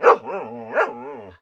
bdog_panic_4.ogg